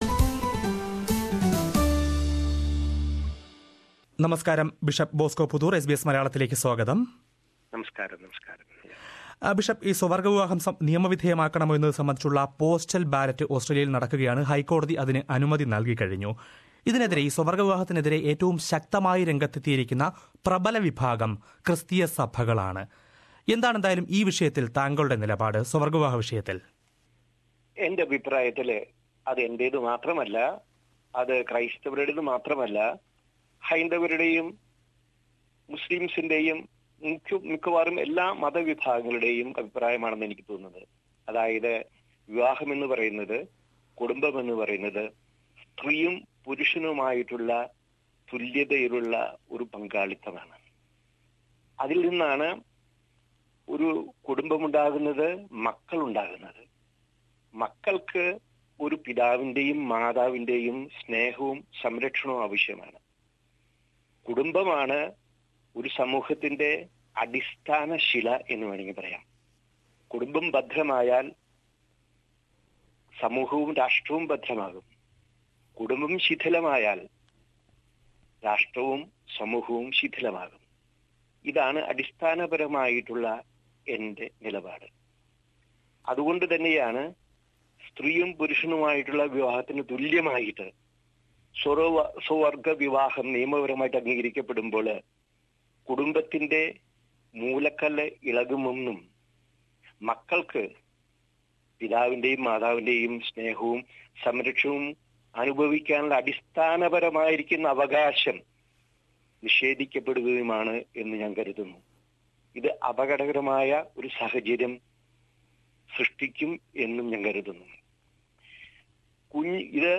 സ്വവർഗ്ഗവിവാഹവും കത്തോലിക്കാസഭയുടെ നിലപാടും: ബിഷപ്പ് ബോസ്കോ പുത്തൂരുമായി അഭിമുഖം
സിറോ മലബാർ സഭയുടെ മെൽബൺ രൂപതാ ബിഷപ്പ് മാർ ബോസ്കോ പുത്തൂരുമായി ഈ വിഷയത്തിൽ എസ് ബി എസ് മലയാളം റേഡിയോ നടത്തിയ അഭിമുഖം കേൾക്കാം.